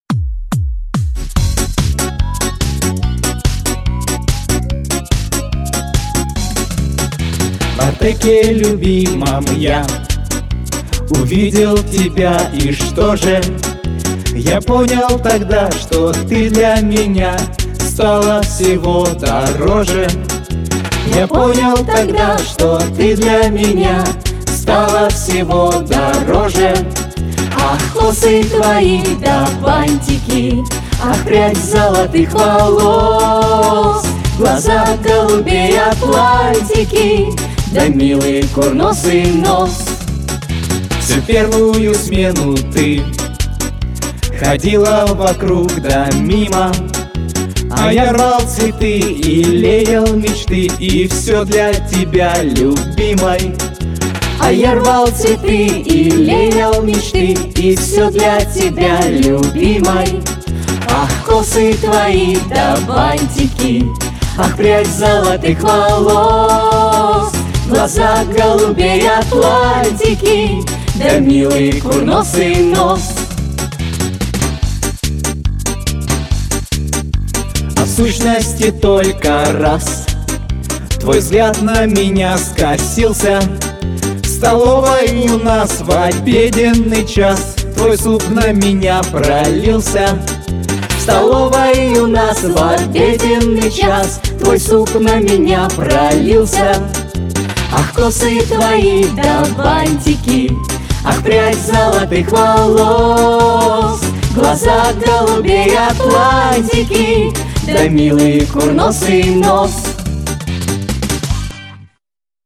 Песни из театральных постановок
в исполнении артистов Учительского театра